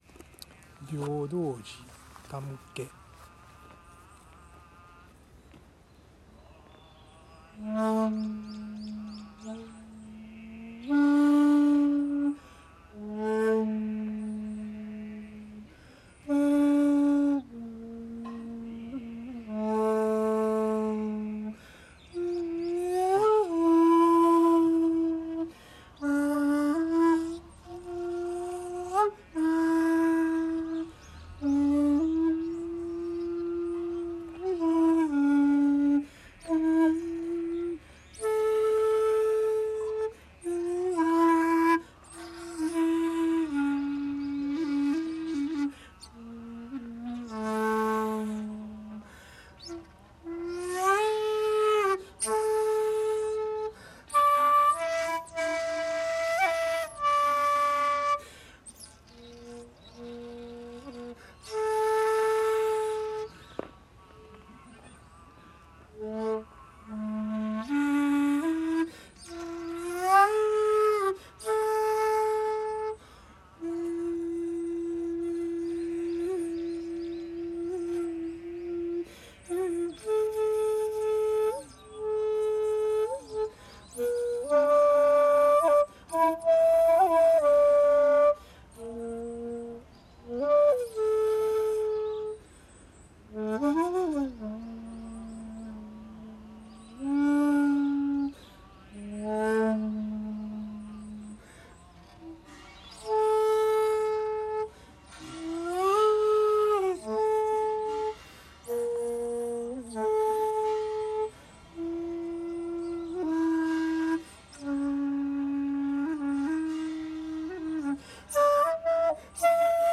いつものようにお経をあげて今回最初の札所なので尺八を吹奏しました。
◆◆　（尺八音源：平等寺にて「手向」）
ゆっくりと尺八を吹き終え、平等寺を出てすぐのところにある民宿「山茶花」に向かいました。